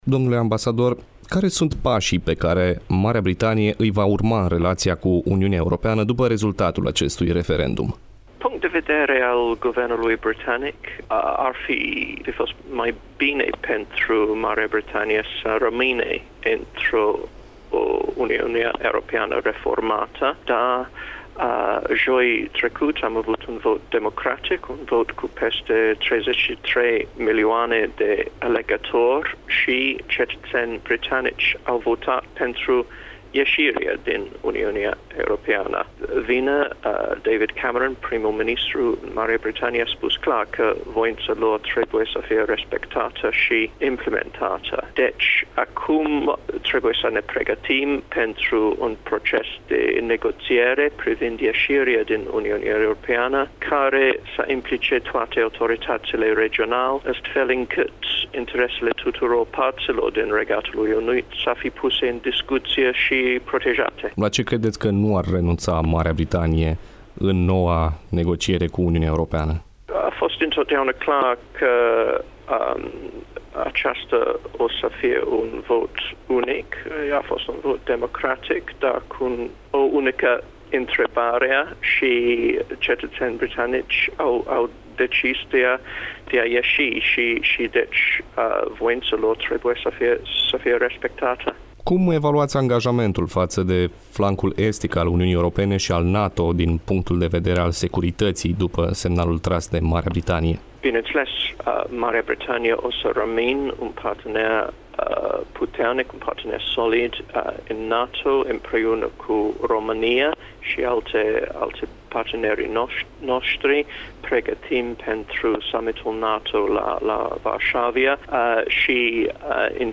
(INTERVIU) Ambasadorul Paul Brummell, despre BREXIT: "Marea Britanie rămâne un partener important și solid pentru România" - Radio Iaşi – Cel mai ascultat radio regional - știri, muzică și evenimente
27-iunie-ora-Interviu-Ambasador-Marea-Britanie.mp3